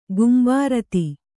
♪ gumbārati